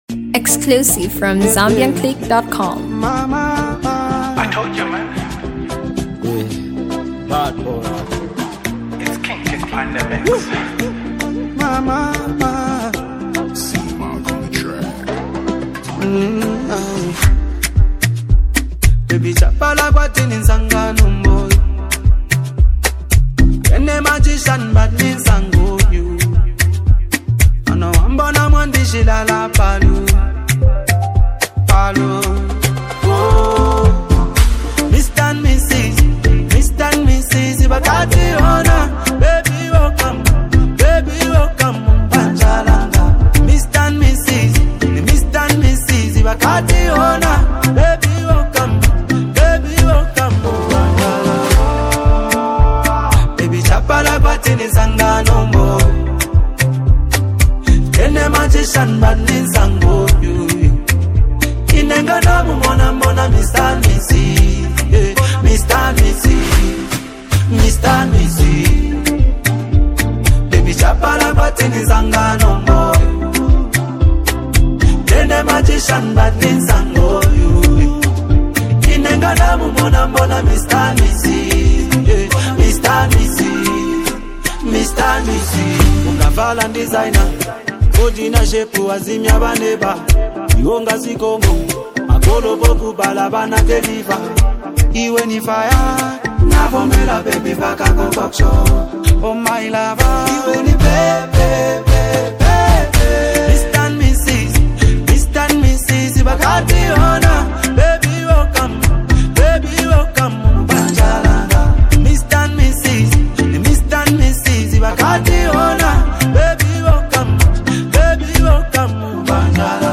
heartfelt love anthem
smooth melodies with relatable lyrics